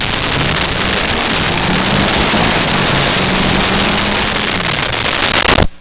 Speed Bike Rev By 01
Speed Bike Rev By 01.wav